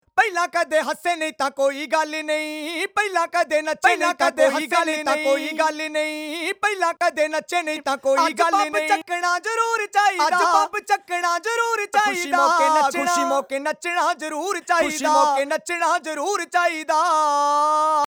Professionally recorded samples at different key and Bpm
100 Punjabi algozey best folk loops